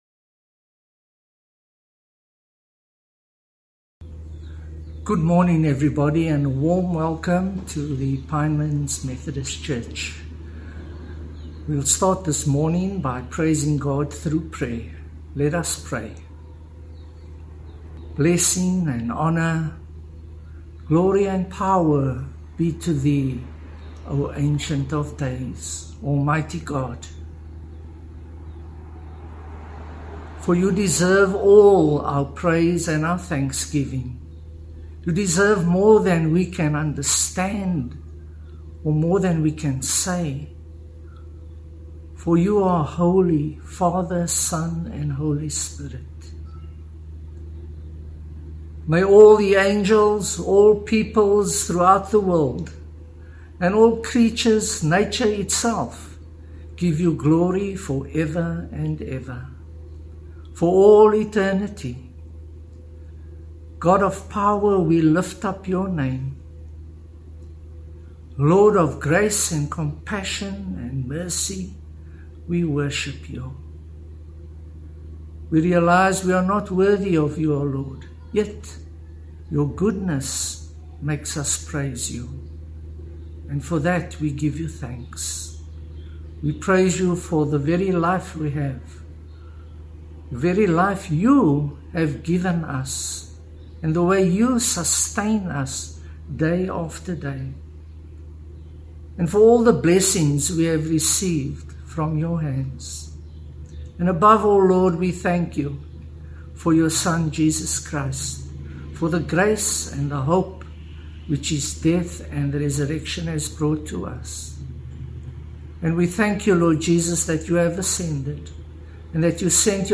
Sermon 15th September A brand plucked from fire